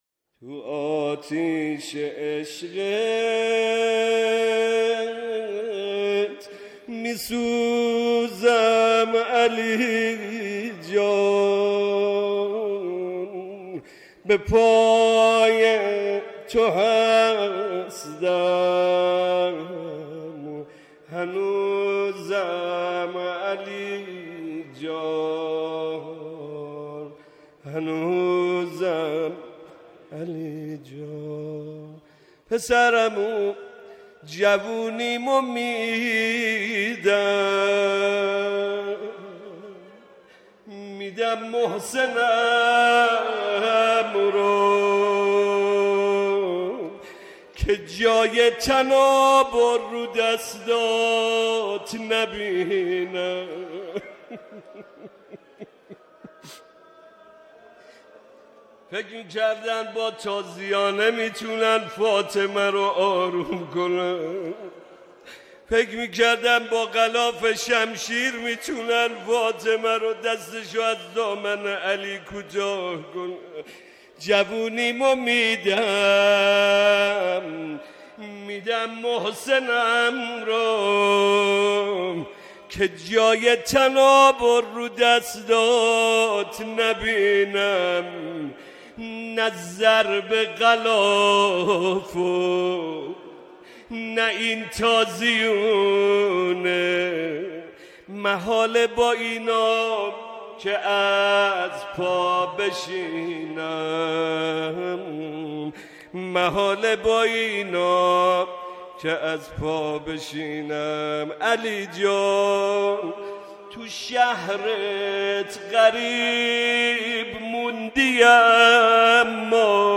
مداحی محمد طاهری در محضر رهبر انقلاب صوت - تسنیم
محمدرضا طاهری بر مصائب ام‌الائمه حضرت صدیقه کبری (س) به مرثیه‌سرایی و نوحه‌خوانی پرداخت.
به گزارش خبرنگار فرهنگی باشگاه خبرنگاران پویا، شب گذشته، مراسم عزاداری ایام شهادت امّ‌ابیها حضرت فاطمه‌زهرا (س) با حضور رهبر معظم انقلاب اسلامی، جمعی از مسئولان و هزاران نفر از قشرهای مختلف مردم در حسینیه امام خمینی (ره) برگزار شد.